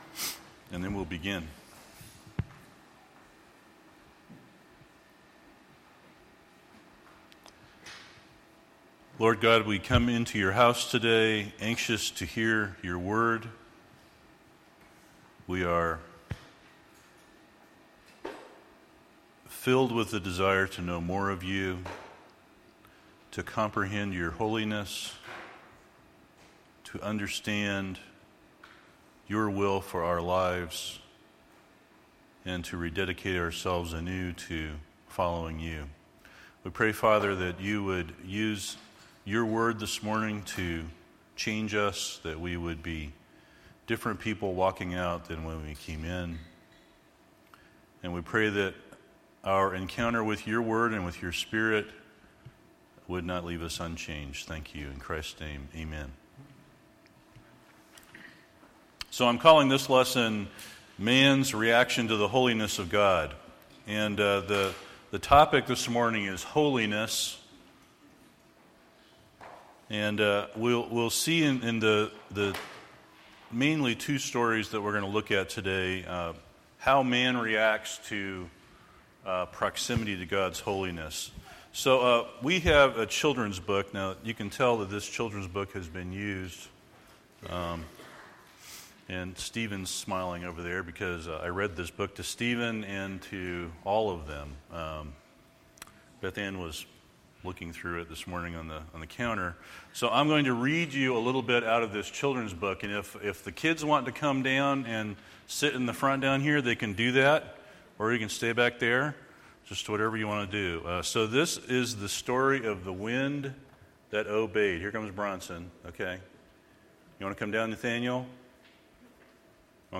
Play the sermon Download Audio ( 25.28 MB ) Email Man's Response to the Holiness of God Details Series: General Topics Date: 2013-09-29 Scripture : Mark 4:35